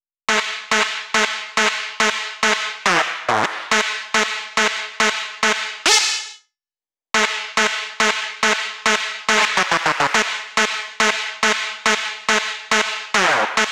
VTDS2 Song Kit 08 Rap The Message Synth Lead.wav